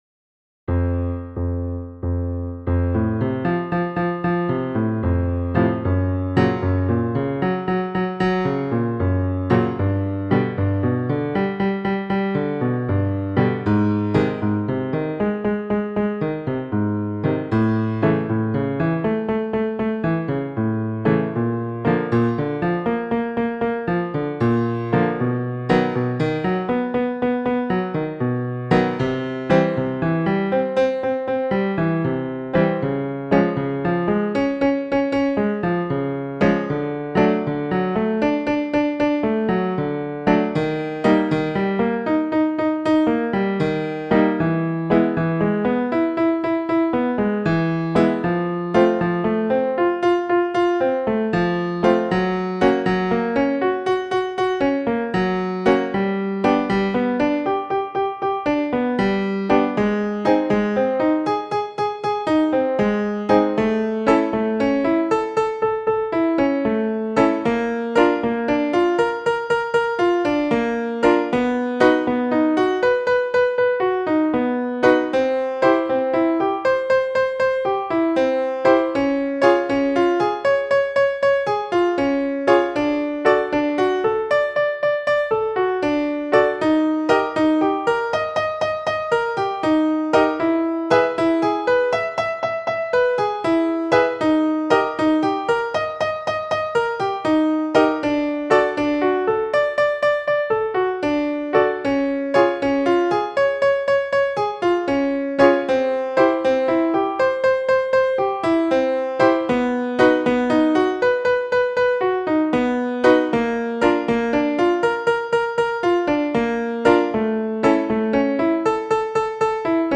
Free Male Vocal Scales -
Ex3.11 – male extended octave F2 – E5
m.f2-E5-extended-octave-s.mp3